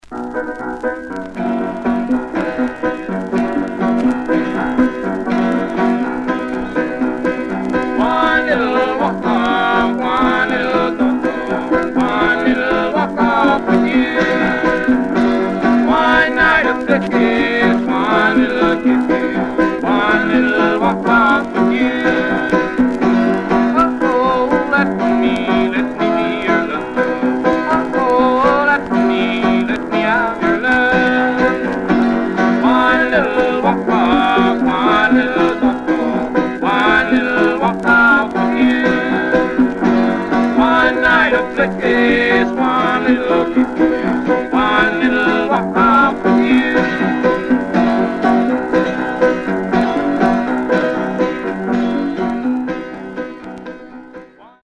Stereo/mono Mono
Country